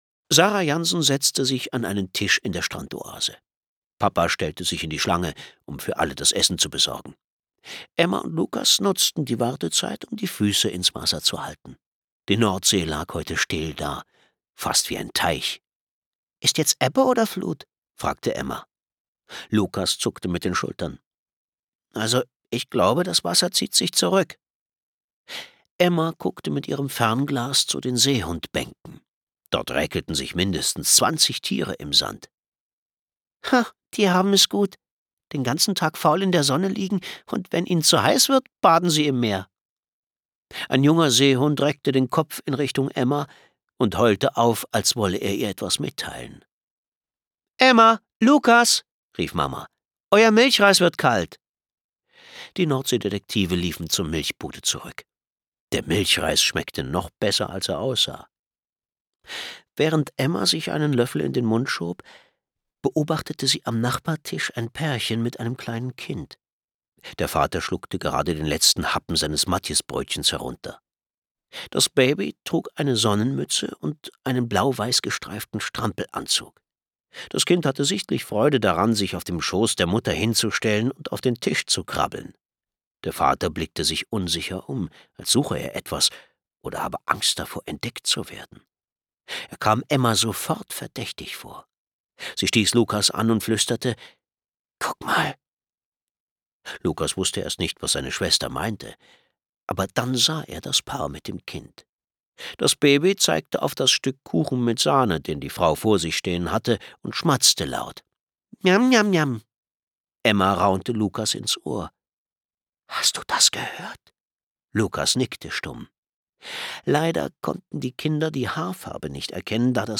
Hörbuch: Die Nordseedetektive.